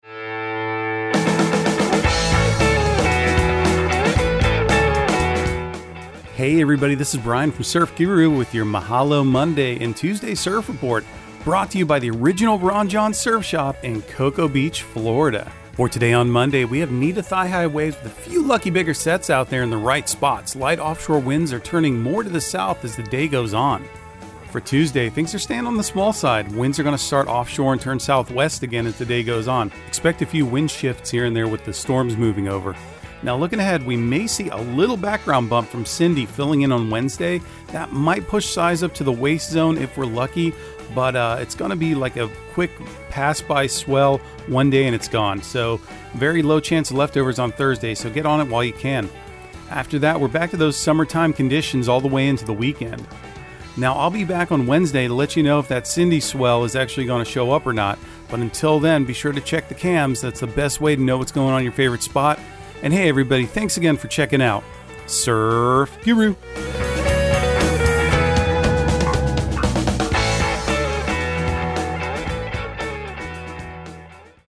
Surf Guru Surf Report and Forecast 06/26/2023 Audio surf report and surf forecast on June 26 for Central Florida and the Southeast. Your host will also enlighten you on current events in the surfing industry and talk about events and entertainment happenings in the local and regional area. Surf Guru is also sure to dig up some new music that will get your feet groovin'.